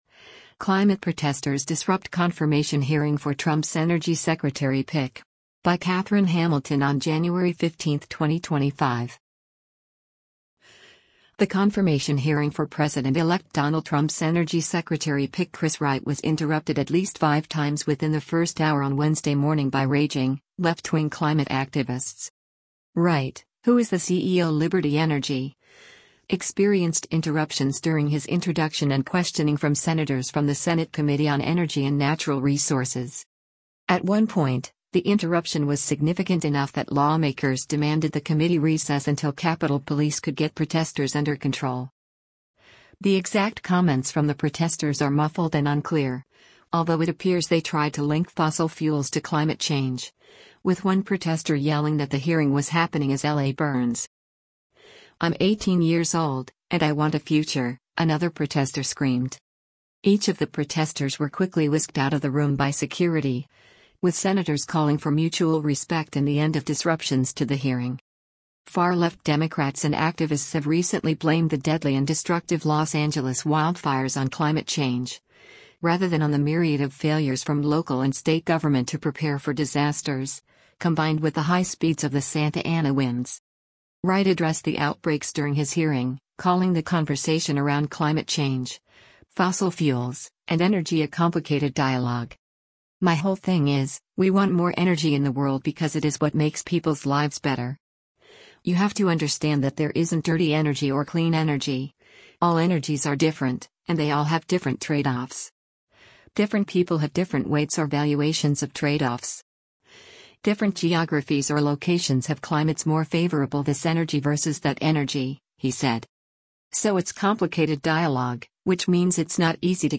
The exact comments from the protesters are muffled and unclear, although it appears they tried to link fossil fuels to climate change, with one protester yelling that the hearing was happening “as L.A. burns.”